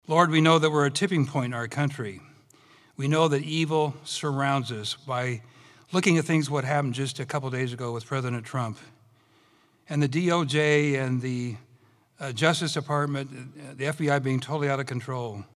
Statewide Iowa — The Iowa Republican Party’s fundraiser on August 10th showcased the GOP’s top of the ticket candidates this November and focused on the presidential campaign that will soon follow.